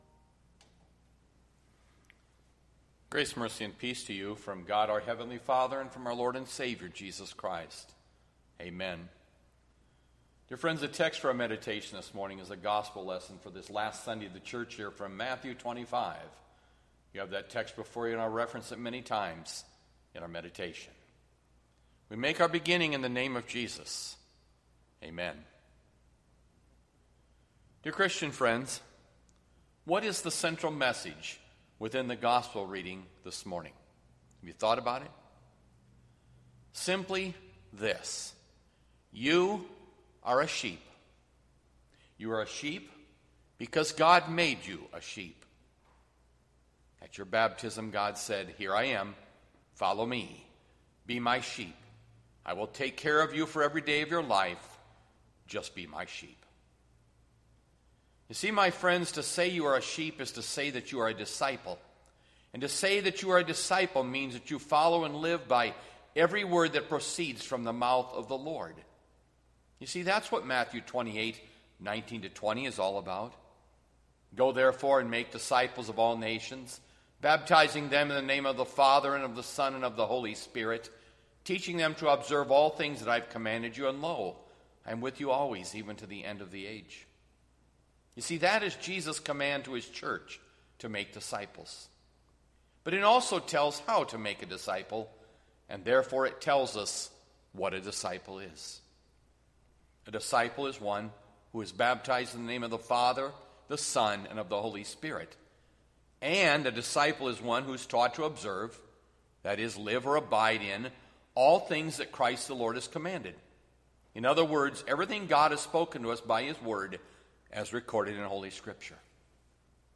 Nov 22, 2020  SERMON ARCHIVE